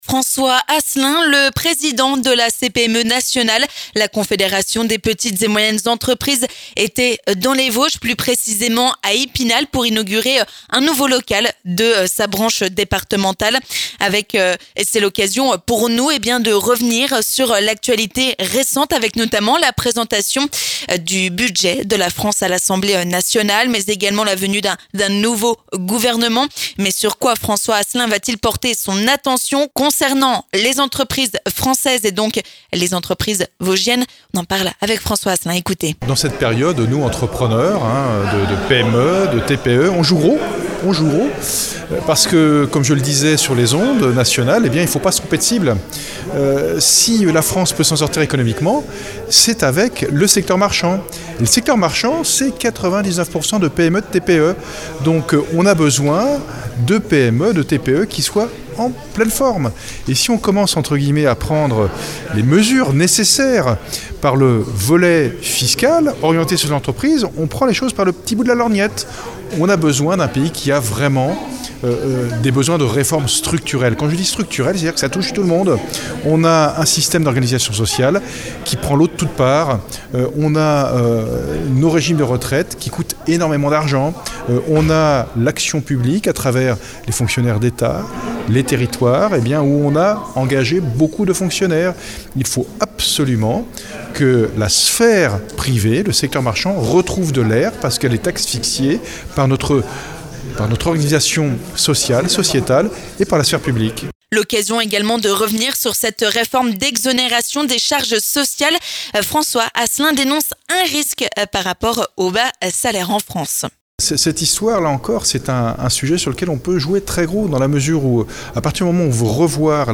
L'occasion pour nous de lui tendre notre micro et de faire le point sur l'actualité : nouveau gouvernement, crainte pour les entreprises locales, baisse des aides pour les apprentis...